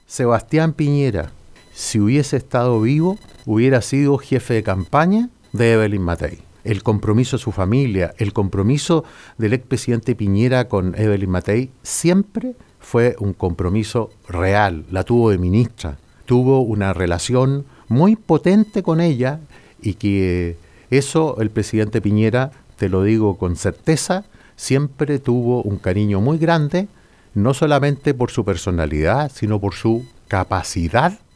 Sus declaraciones las entregó en conversación con Radio Bío Bío en Puerto Montt al ser consultado sobre las próximas elecciones presidenciales.